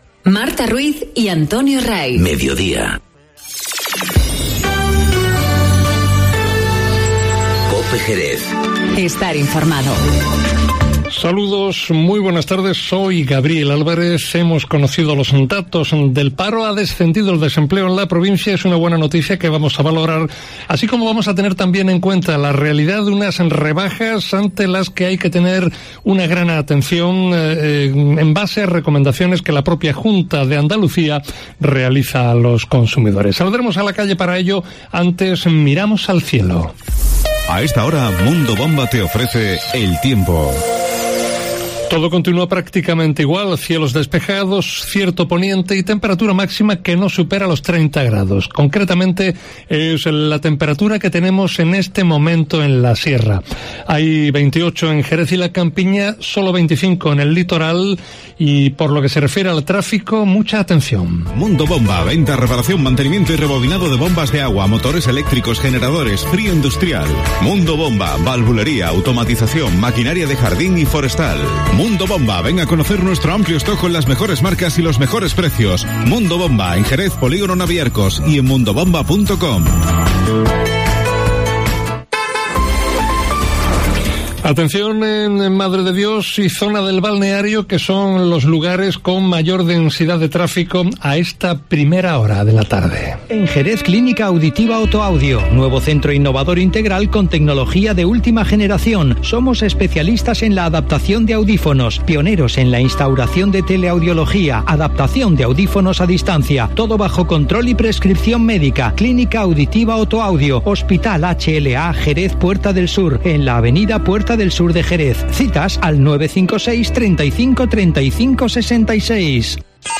Informativo Mediodía COPE en Jerez 02-07-19